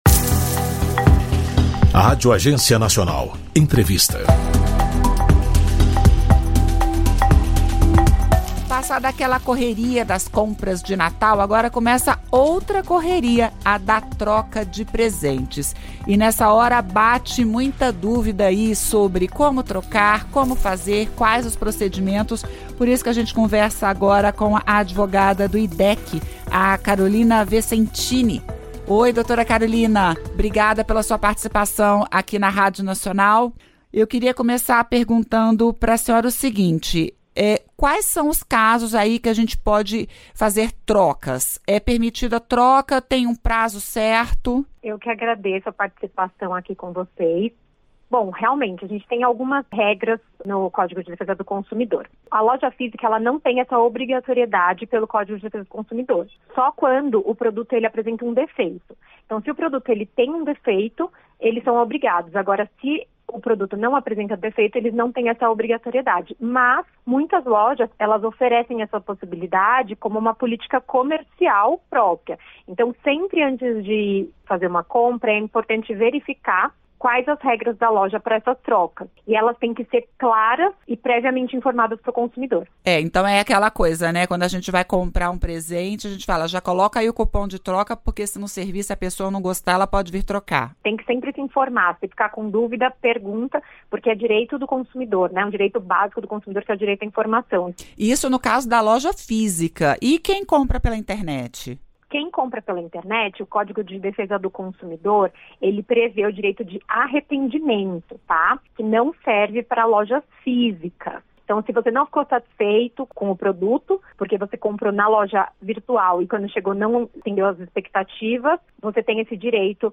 Entrevista: Confira regras para troca de presentes ganhados no Natal